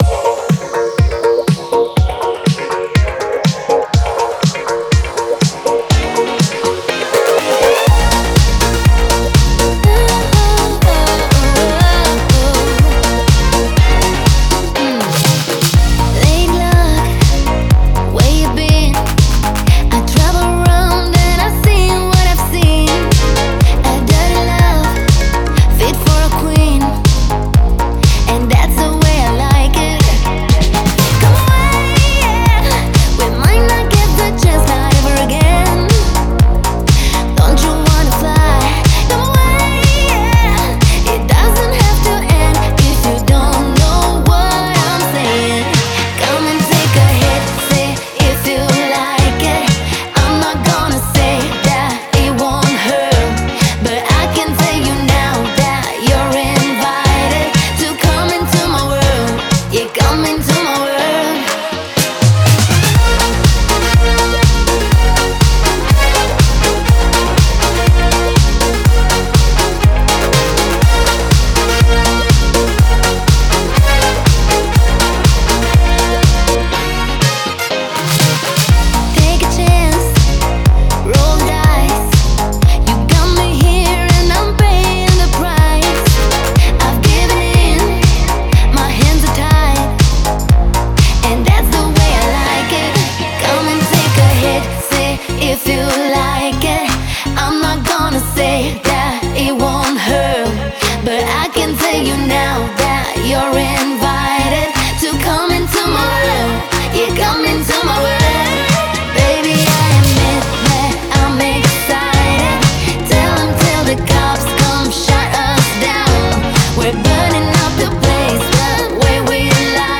энергичная поп-танцевальная композиция